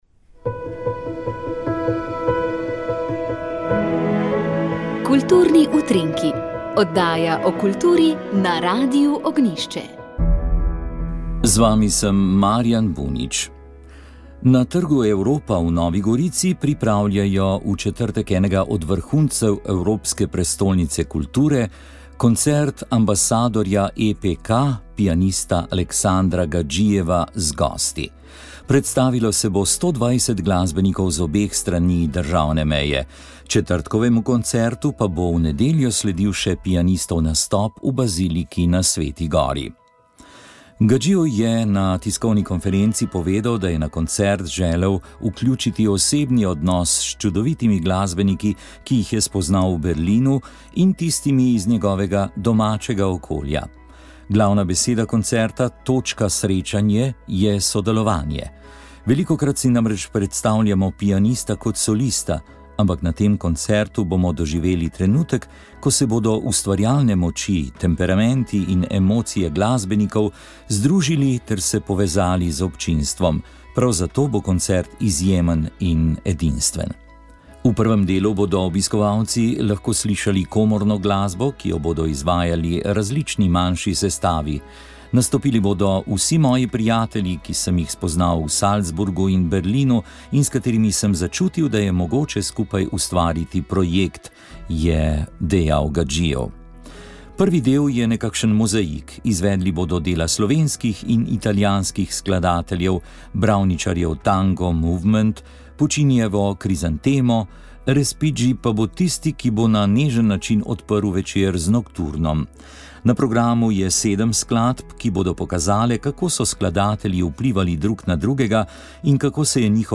Nadaljujemo z objavo referatov, ki so bili predstavljeni na znanstveni konferenci z naslovom Med tradicijo in moderno: slovenski katoliški intelektualci in narodnostno vprašanje.